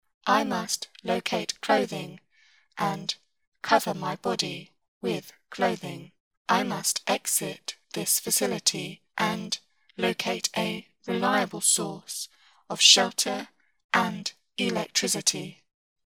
Robot Voice Audio Tutorial
Here is what the Flanger preset “Martians” sounds like:
MELI-example-Flanger-Martians.mp3